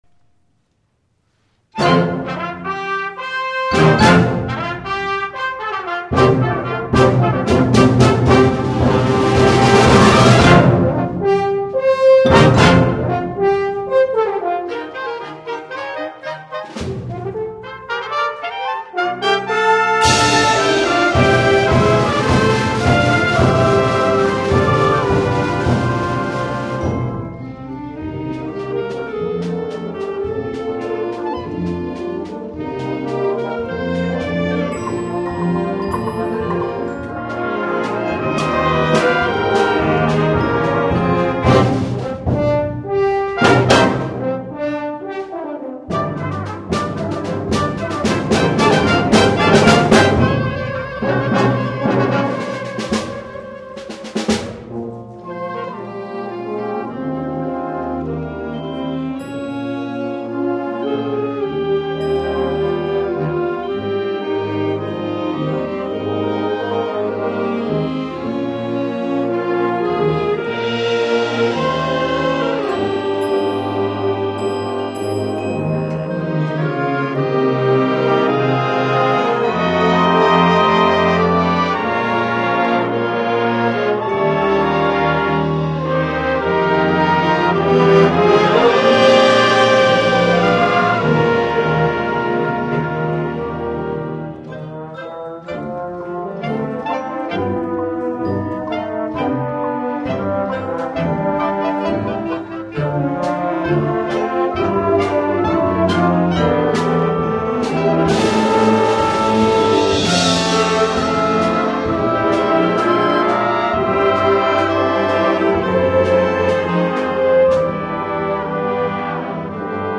From Concert #2, May 5, 2011 MP3 files